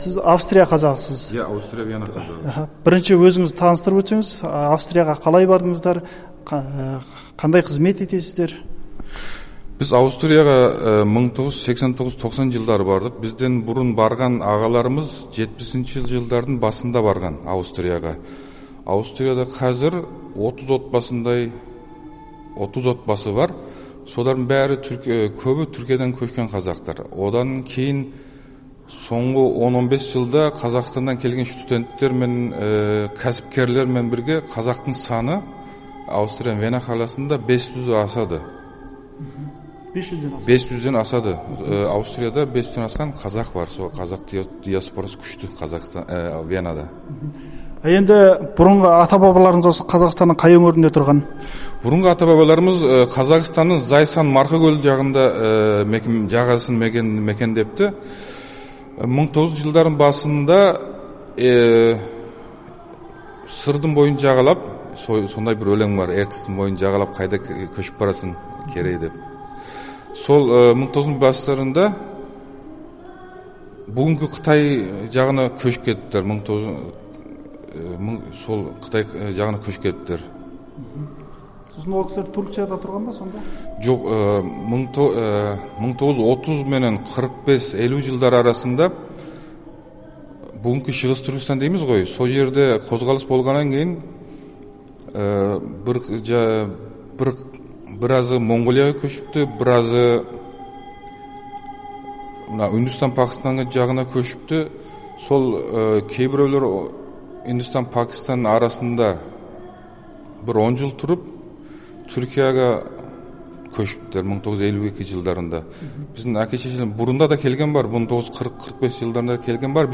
Австрия қазағымен сұхбат